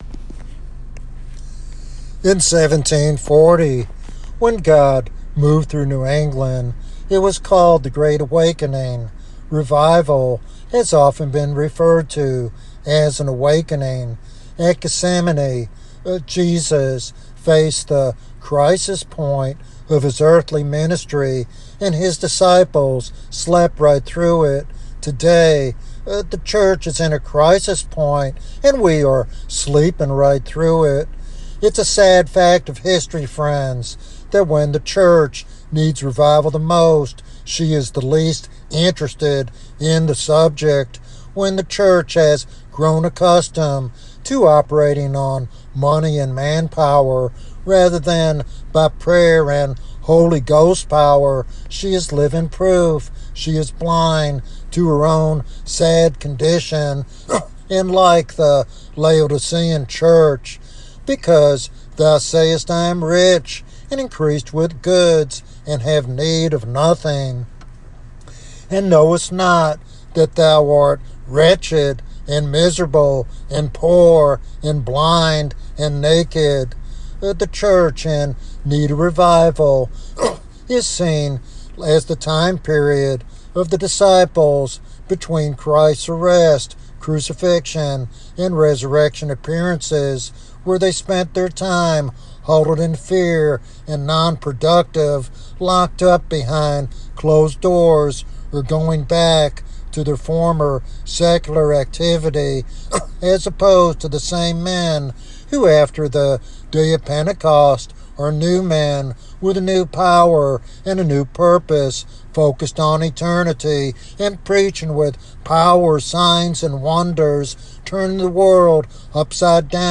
This sermon serves as a passionate call to realign the Church with God’s purposes and to pray fervently for revival in our day.